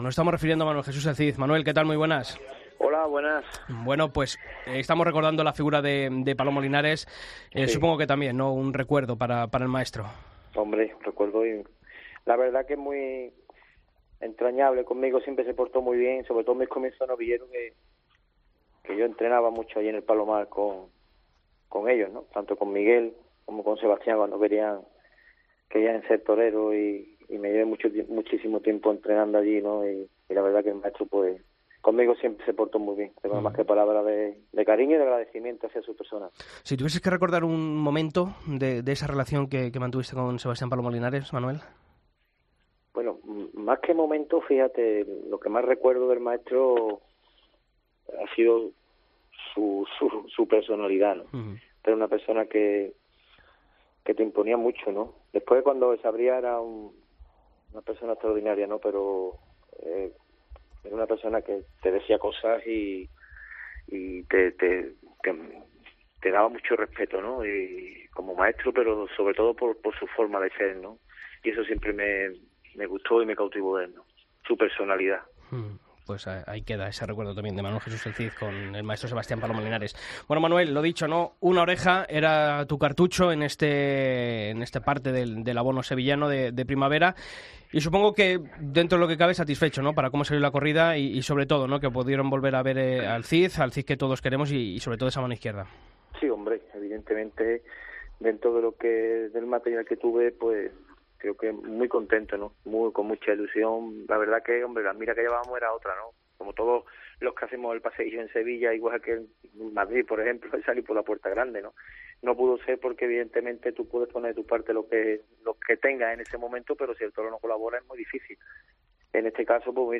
Escucha la entrevista a El Cid en El Albero